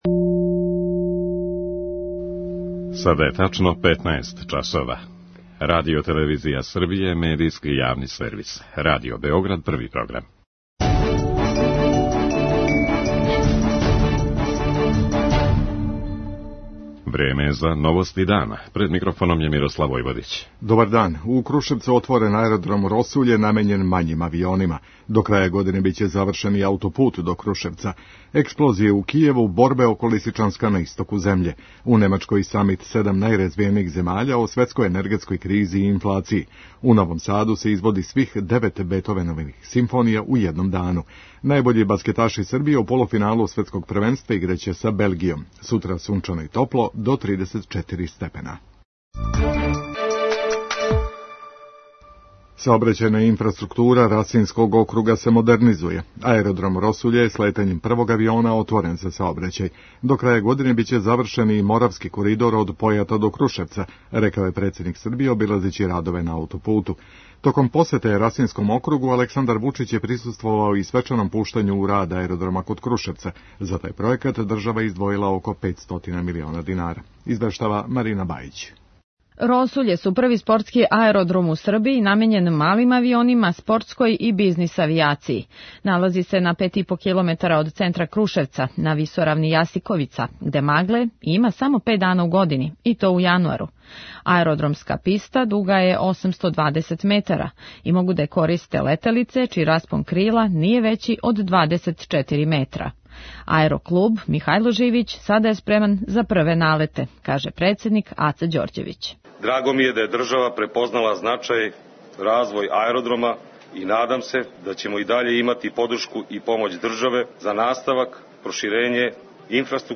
Поручио је да је урађен најтежи део посла и да ће деоница до Крушевца бити готова до краја године. преузми : 5.76 MB Новости дана Autor: Радио Београд 1 “Новости дана”, централна информативна емисија Првог програма Радио Београда емитује се од јесени 1958. године.